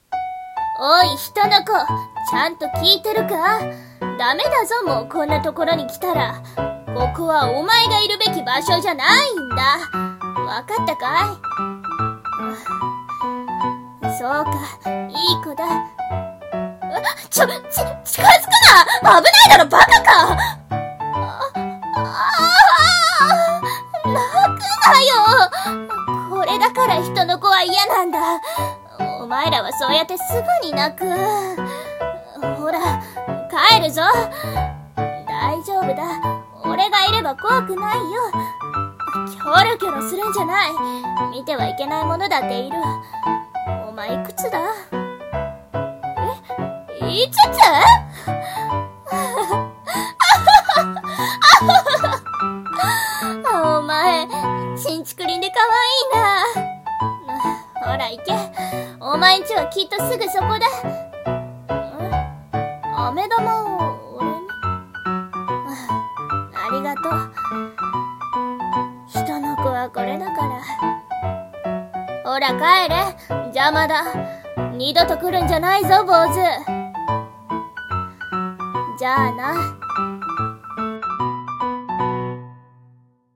声劇】飴玉